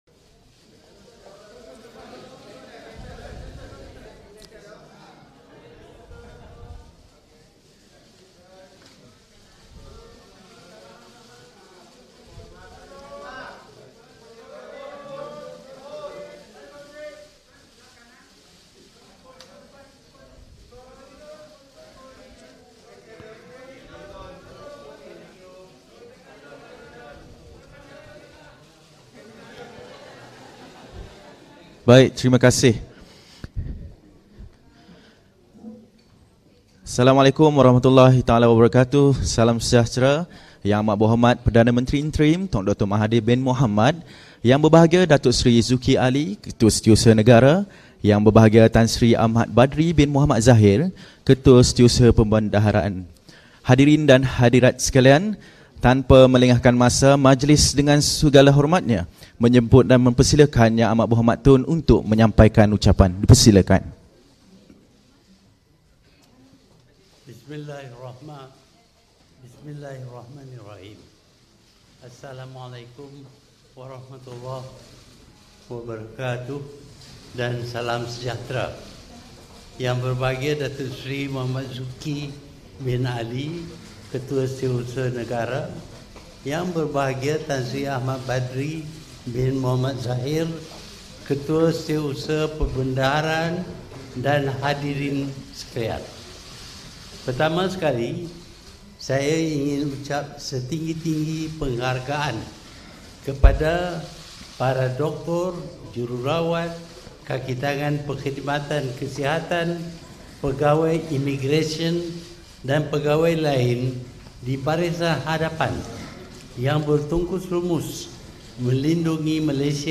[SIDANG MEDIA] COVID-19: Kerajaan umum Pakej Rangsangan Ekonomi 2020